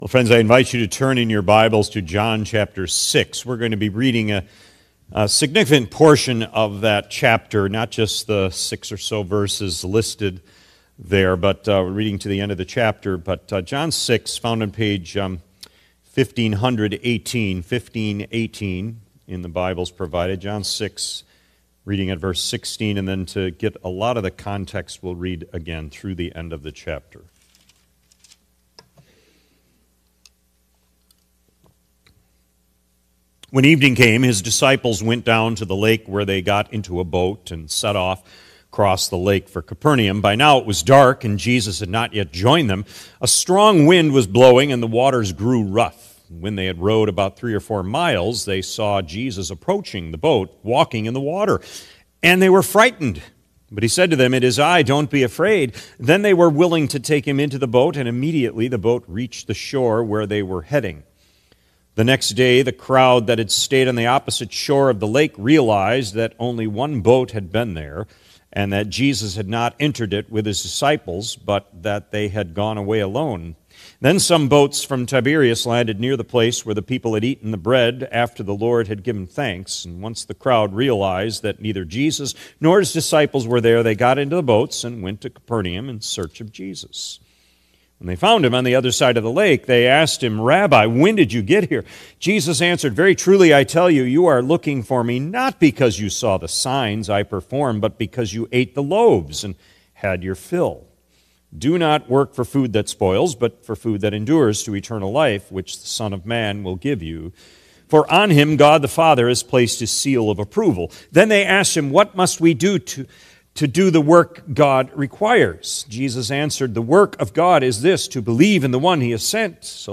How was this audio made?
February 22 2026 P.M. Service